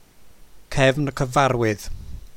“Cefn” means ridge. “Cyfarwydd” means familiar, or a storyteller. To hear how to pronounce Cefn Cyfarwydd press play: